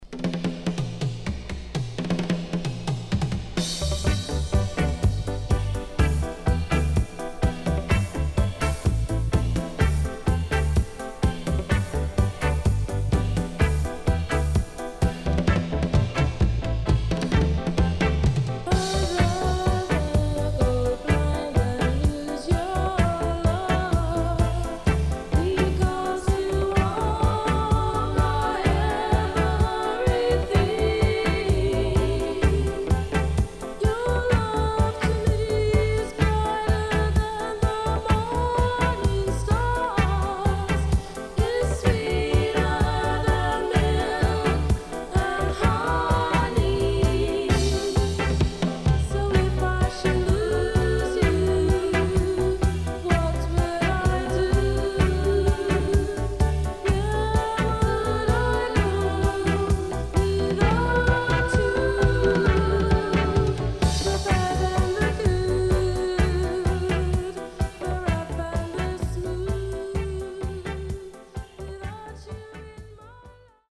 【12inch】
Nice Female Lovers.W-Side Good
SIDE A:少しチリノイズ入ります。